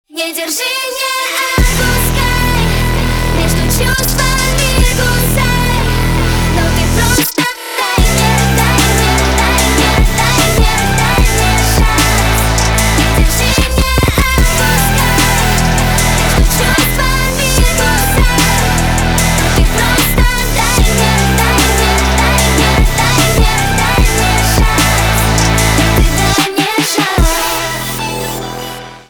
поп
ремиксы , битовые , басы , качающие
громкие